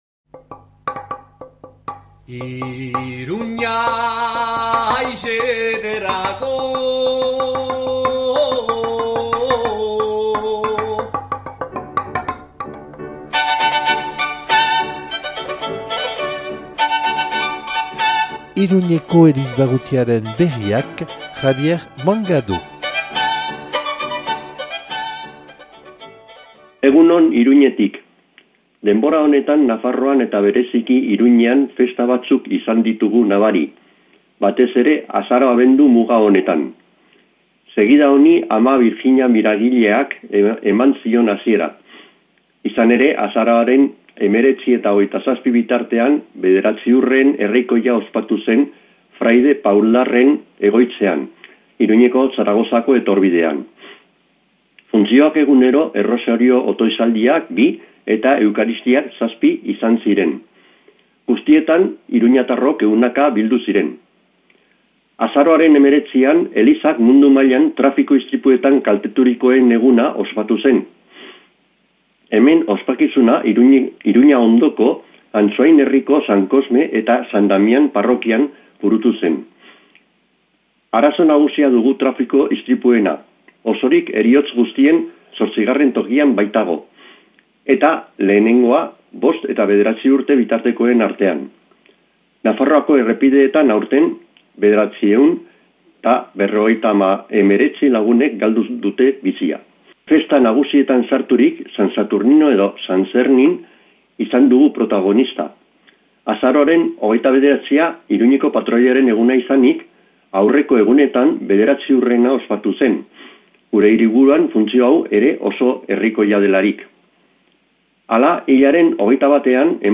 Une émission présentée par
Présentateur(trice)